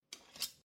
Metal free sound effects